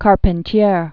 (kärpĕn-tyār), Alejo 1904-1980.